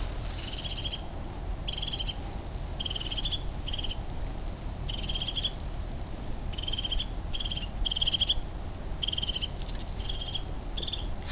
Vogelstimmen: Zaunkönig,
Haubenmeise
Haubenmeise.wav